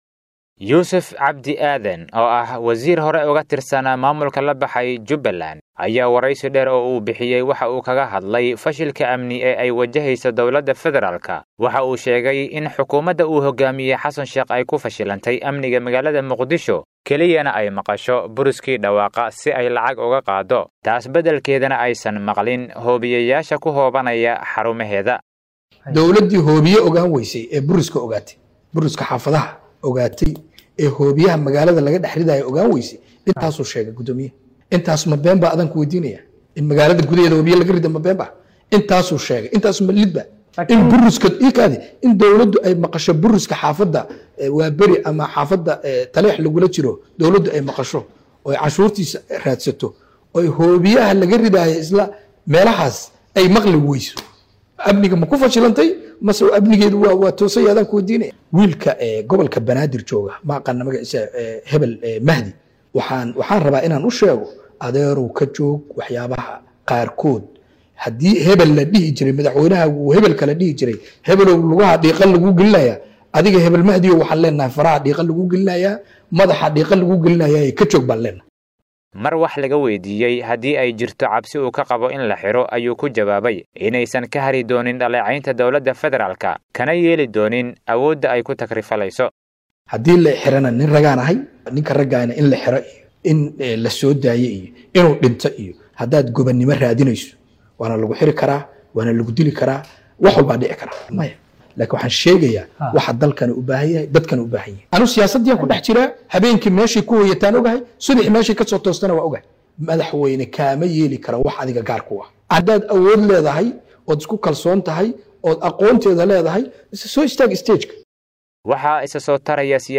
Yuusuf Cabdi Aadan oo ah wasiir hore uga tirsanaa maamulka la baxay Jubbaland, ayaa wareysi dheer oo uu bixiyay waxa uu kaga hadlay fashilka amni ee ay wajheyso Dowladda Fedaraalka.